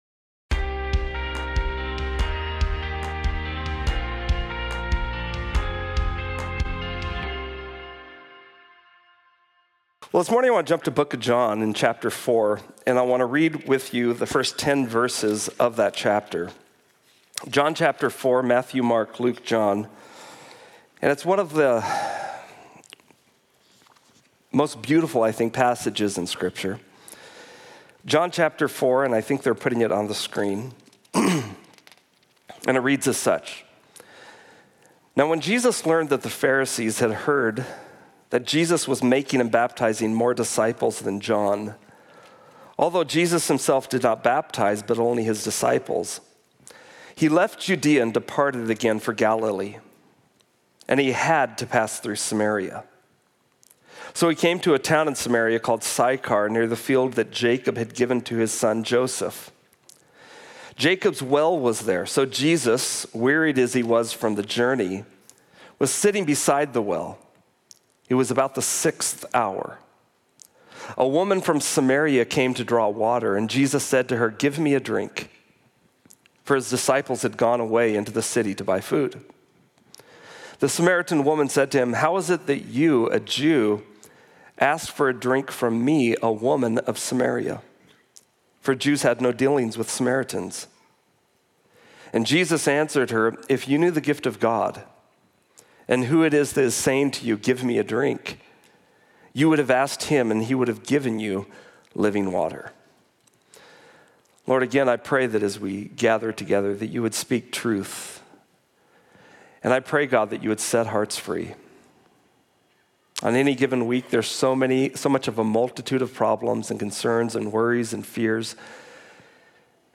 Give Me A Drink - John 4:1-10 Calvary Spokane Sermon Of The Week podcast